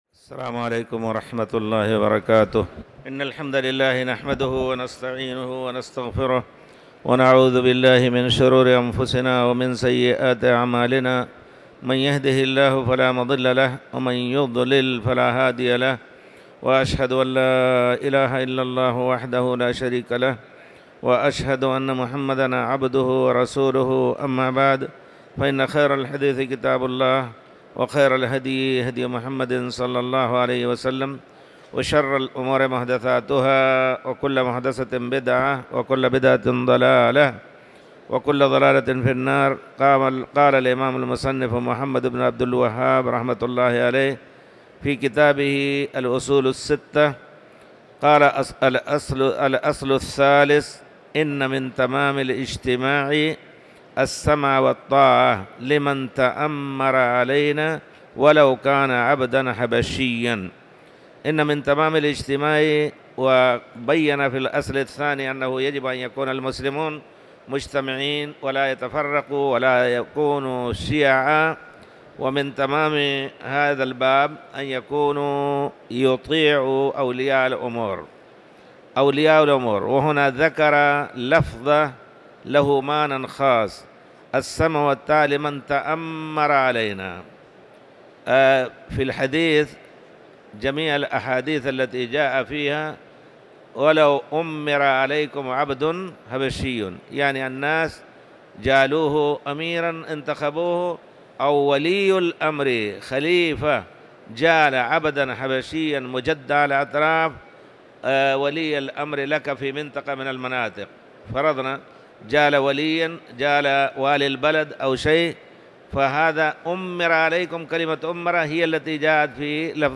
تاريخ النشر ٢٤ رمضان ١٤٤٠ هـ المكان: المسجد الحرام الشيخ